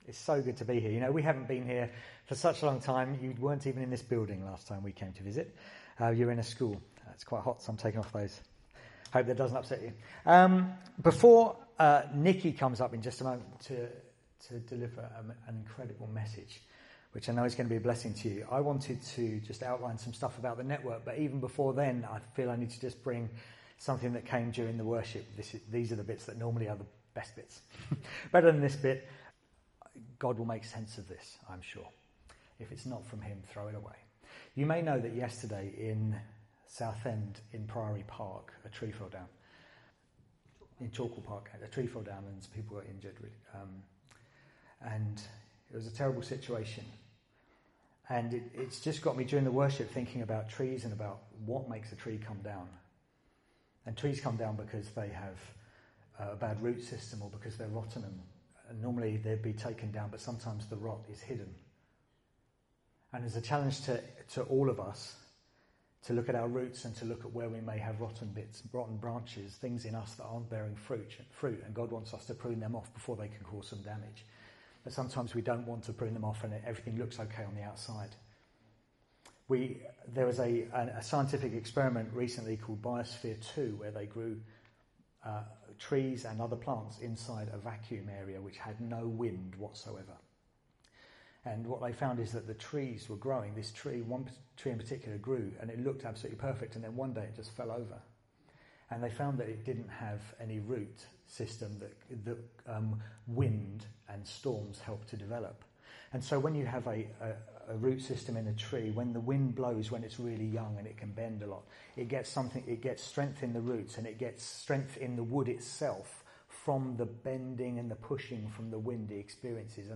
Teachings from our Sunday that don’t form part of a series.